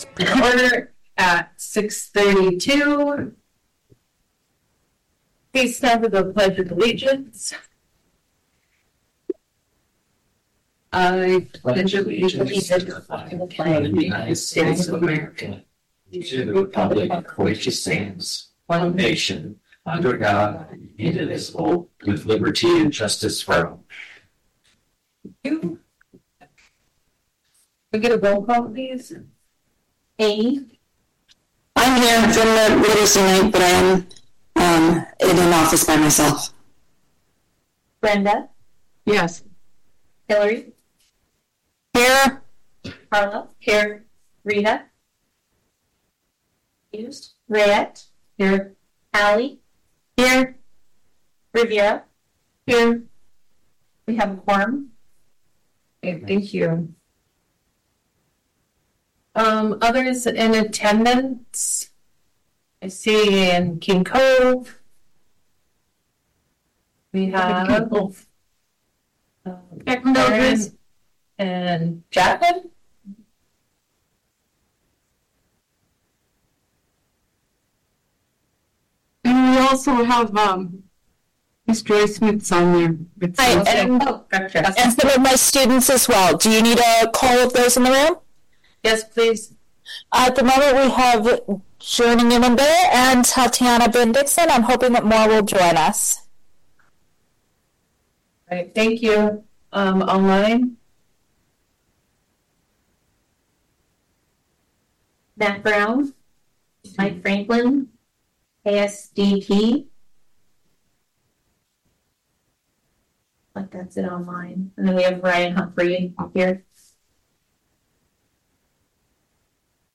MP3+PDF: Regular Meeting of the AEBSD School Board at 6:30PM on Tues, Nov 25, 2025
The Aleutians East Borough School District is holding a Regular Meeting of the School Board on Tuesday, November 25th, 2025 at 6:30PM. This meeting is open to the public at the District Office in Sand Point, borough schools & via Zoom.